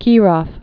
(kērôf, kyērəf)